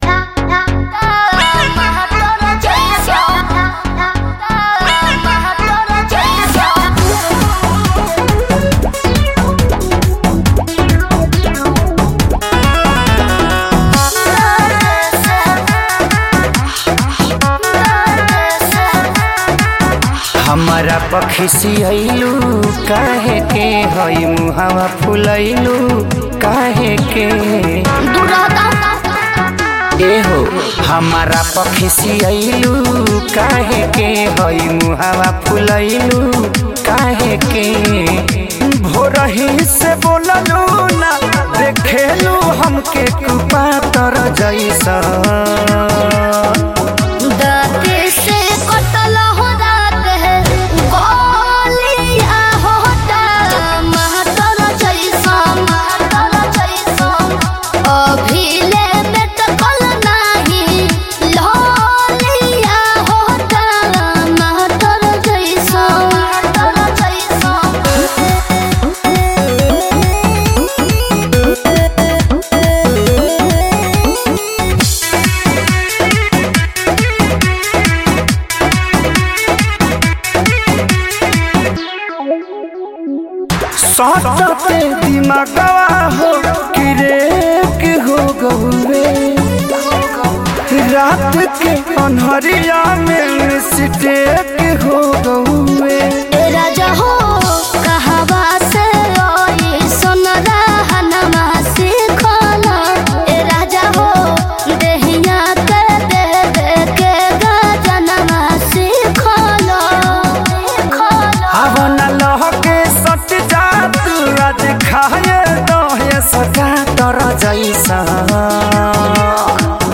Bhojpuri Mp3 Songs